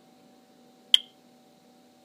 I’ve attached a sample, which is the low battery warning chirp of a smoke alarm. Even if I slow it way down, I still have the characteristic hard edge of a transient noise, and that appears to be the scary part.